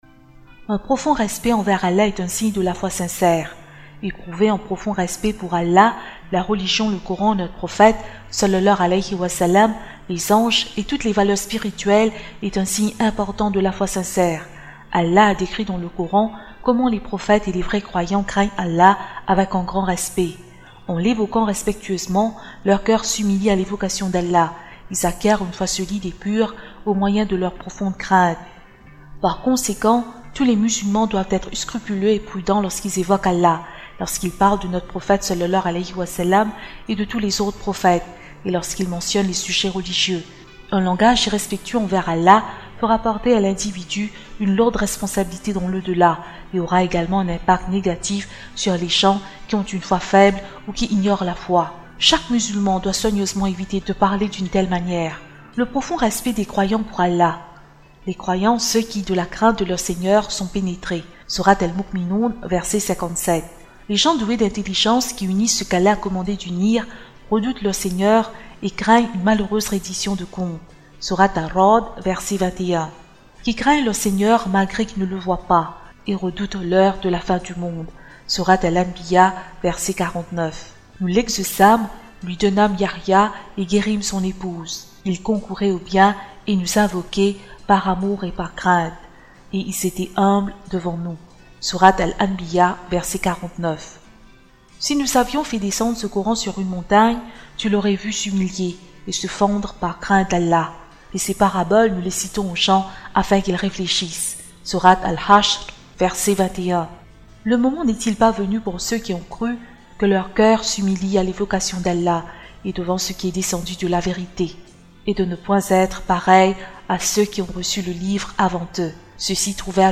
1- A quoi la crainte d'Allah devrait-elle ressembler ? Extrait de l'interview d'Adnan Oktar en direct sur Ekin TV (2 mars 2009) ADNAN OKTAR : La crainte d'Allah est comme la crainte d'un amoureux fou qui s'abstient de ...